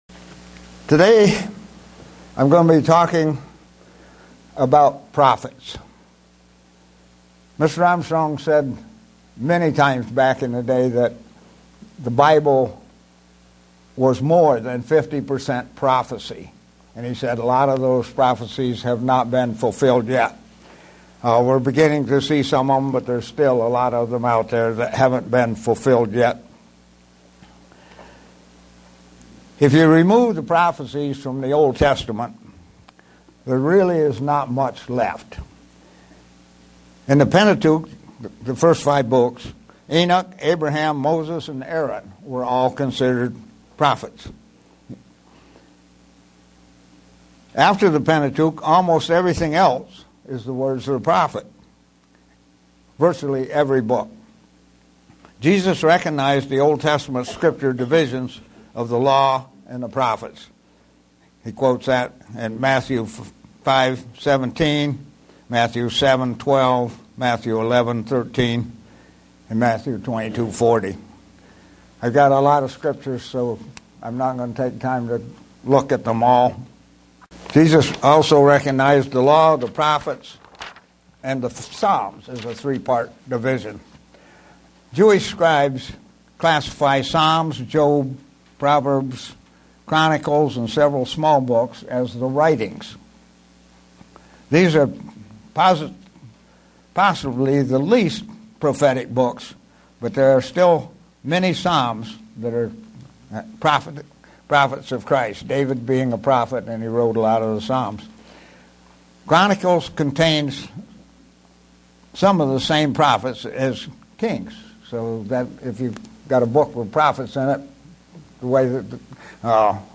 Given in Buffalo, NY
Print Using the Bible to determine if someone is a Prophet today. sermon Studying the bible?